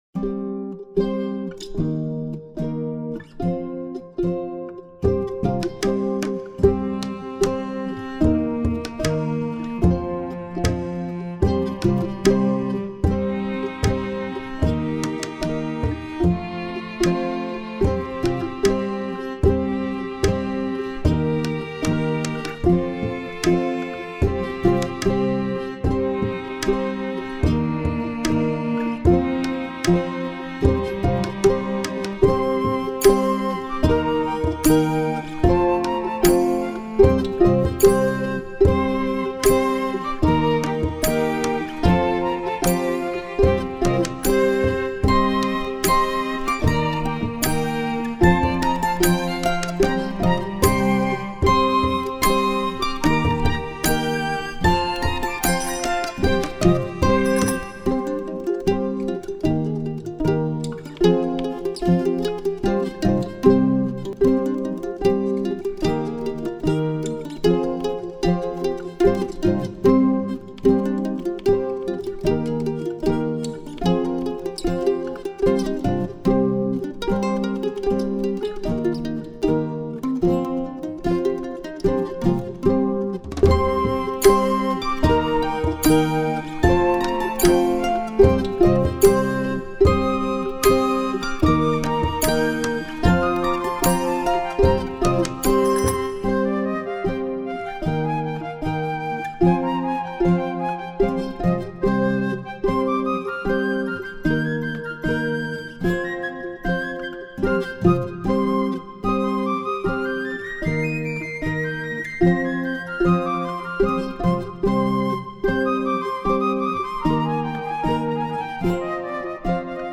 موسییقی بی کلام 4